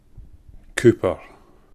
Cupar (/ˈkpər/